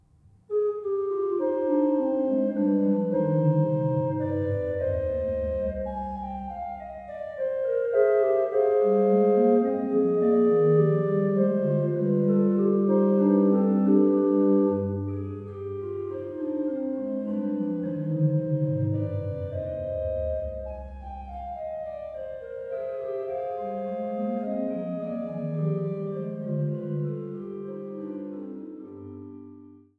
Sie greift auf Tugenden des barocken Orgelbaus zurück, ohne jedoch eine barocke Stilkopie darzustellen. Die Vielfarbigkeit der nichtsdestotrotz sehr übersichtlichen Disposition ermöglicht eine beeindruckende musikalische Transparenz, und für die Wiedergabe von Werken Johann Sebastian Bachs erweist sich das Instrument als überaus geeignet.